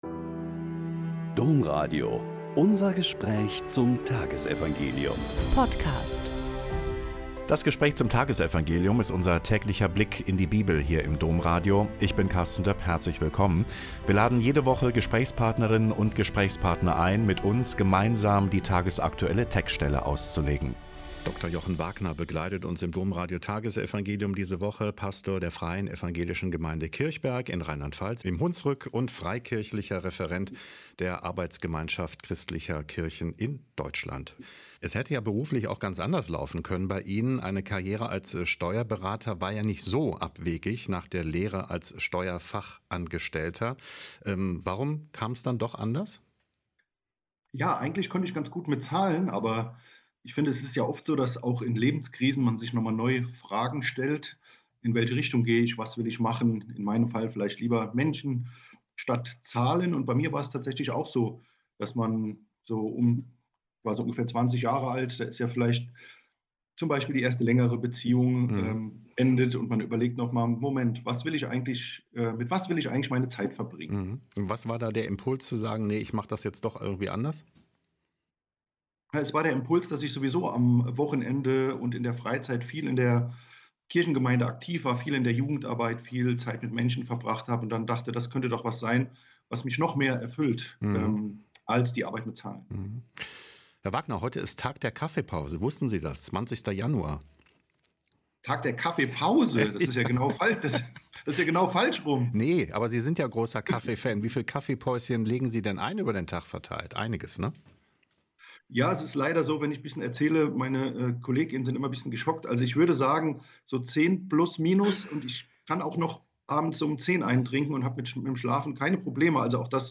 Mk 2,23-28 - Gespräch